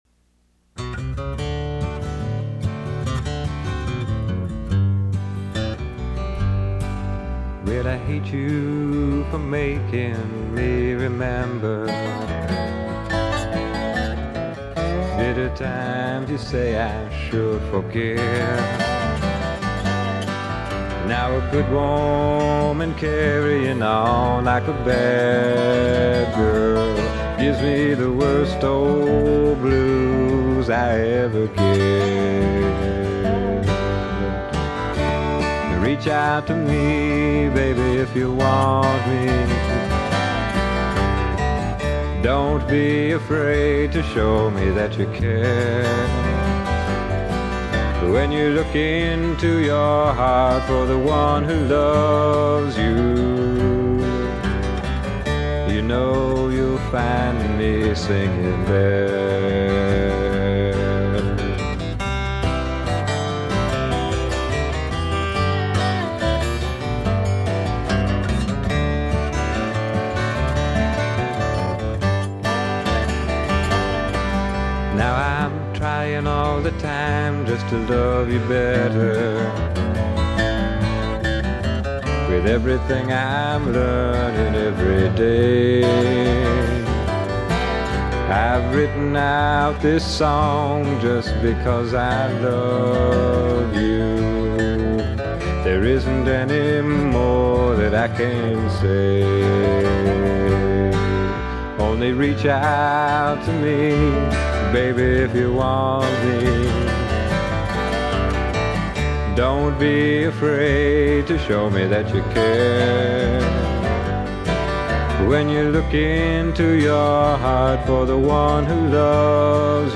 These first cuts were recorded at Studio 80 in Minneapolis on December 27, 1971.